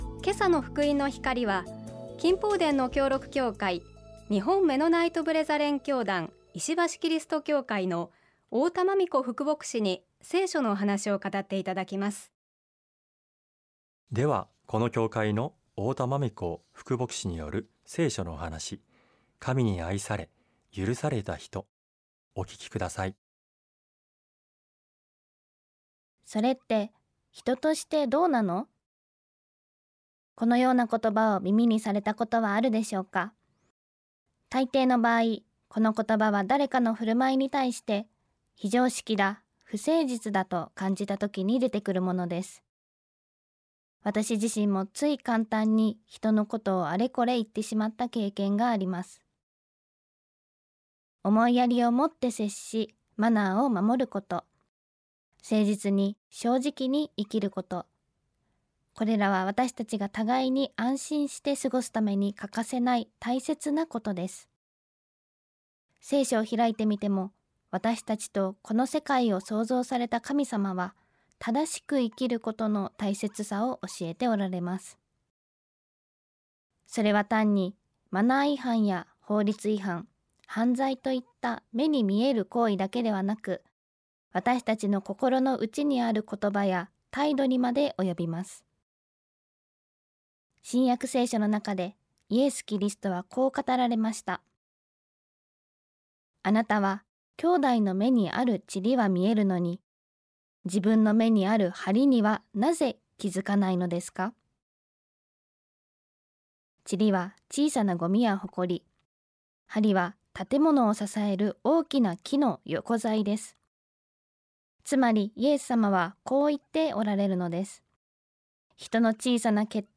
聖書のお話